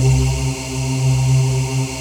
VOICEPAD21-LR.wav